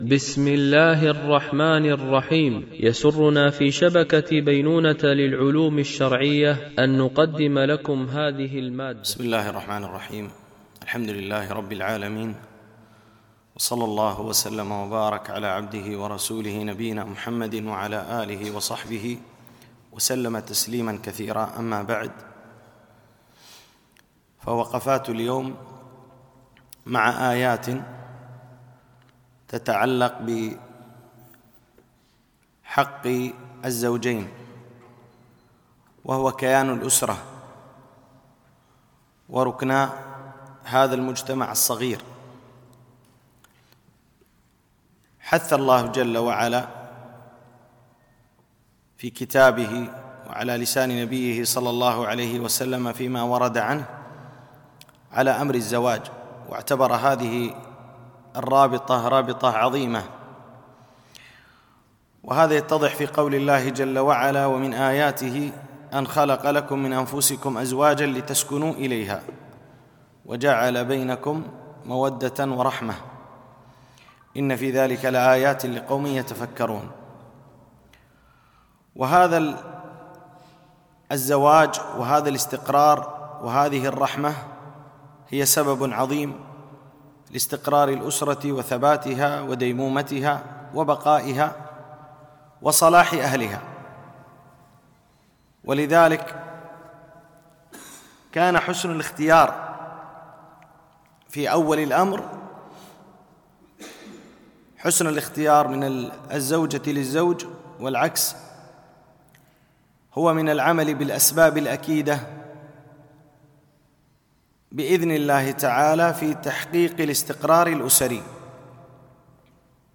وقفات مع آيات ـ الدرس 08 ( حق الزوجين )